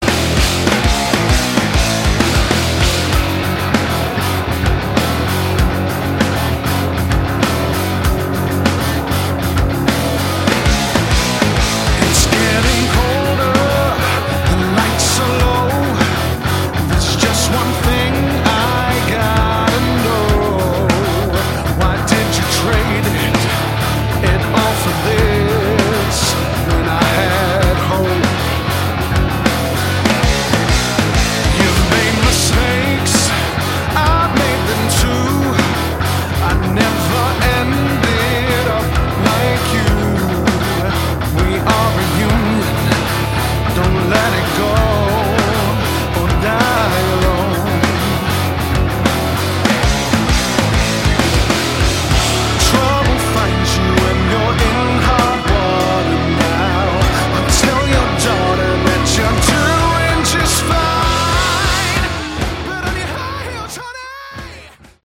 Category: Hard Rock
bass, guitar, vocals
drums